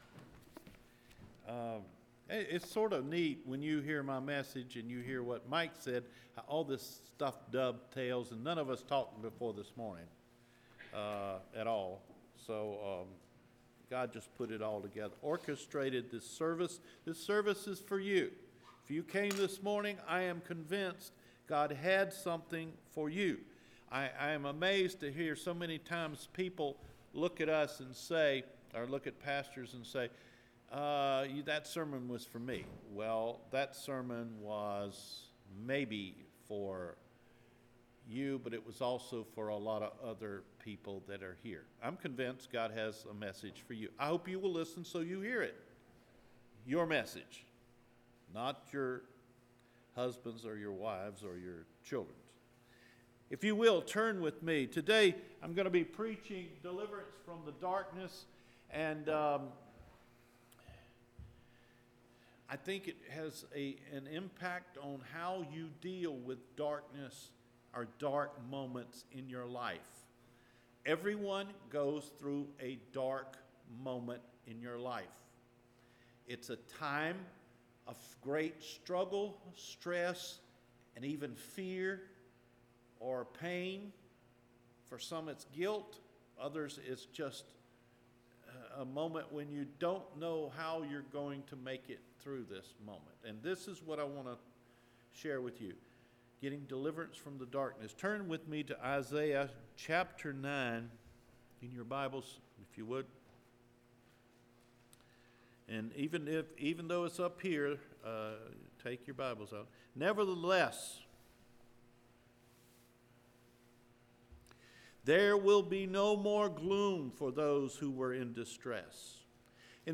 DELIVERANCE FROM DARKNESS – NOVEMBER 10 SERMON